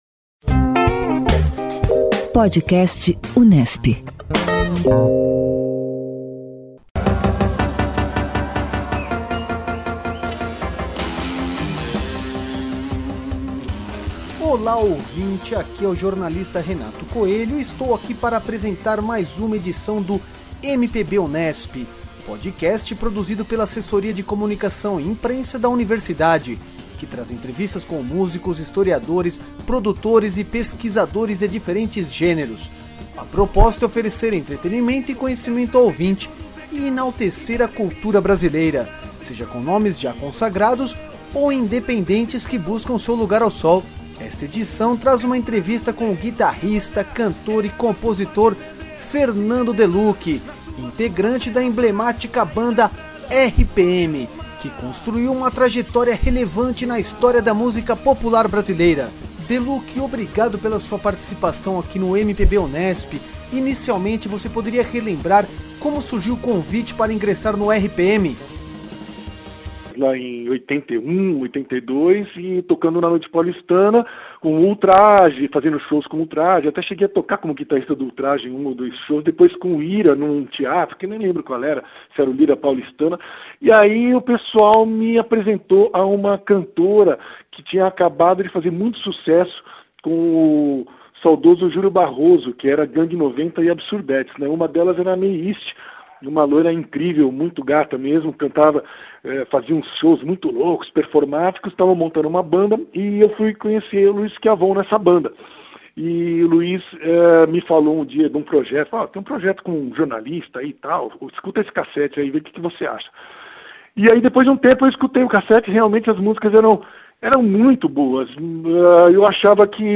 Esta edição do MPB Unesp traz uma entrevista com o guitarrista, cantor e compositor Fernando Deluqui, integrante da emblemática banda RPM, que construiu uma trajetória relevante na história da música popular brasileira. Deluqui relembra como surgiu o convite para tocar no RPM, passagens e sinaliza novos trabalhos.